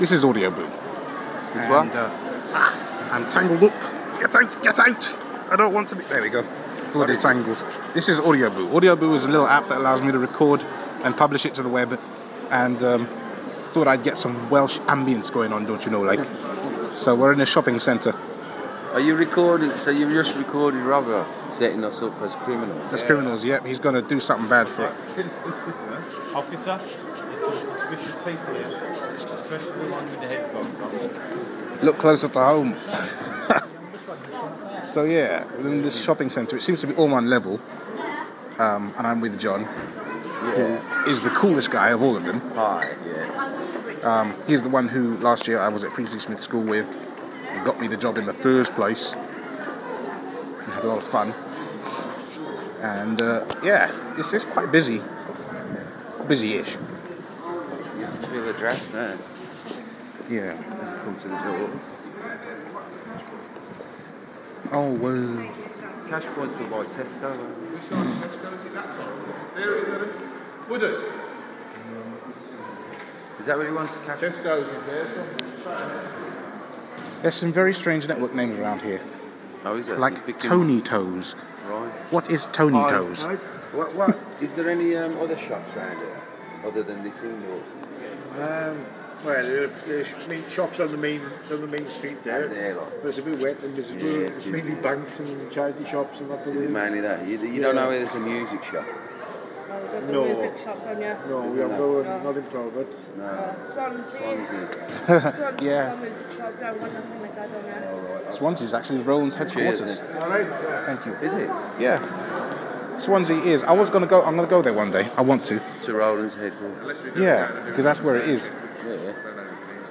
At the Aberafan shopping Center in Port Talbot.